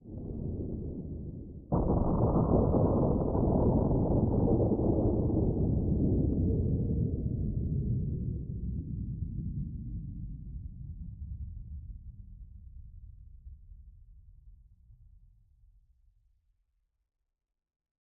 Minecraft Version Minecraft Version 1.21.5 Latest Release | Latest Snapshot 1.21.5 / assets / minecraft / sounds / ambient / nether / nether_wastes / mood2.ogg Compare With Compare With Latest Release | Latest Snapshot